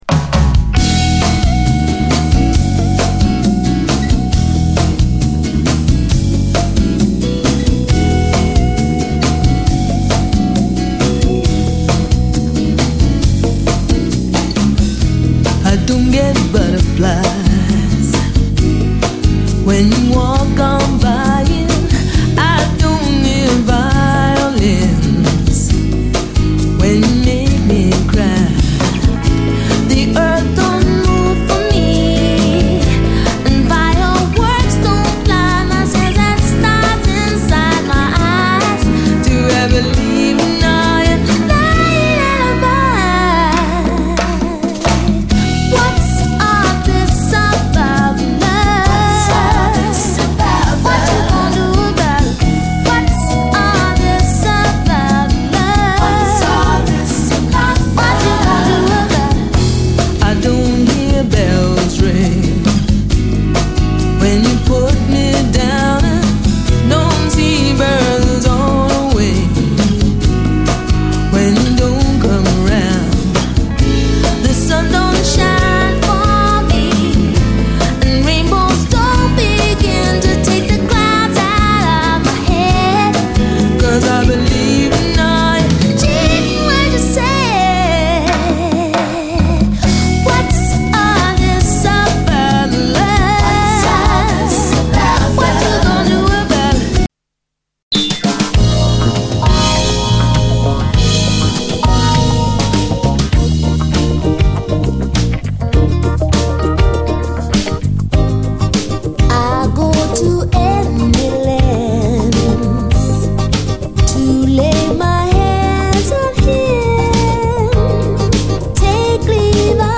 SOUL, 70's～ SOUL
70’S当時と変わらぬキュート・ヴォイスと優しいソング・ライティングに感動！
ハワイアン・ソウルばりの爽快ソウル
アコースティック・ブラジリアン・ソウル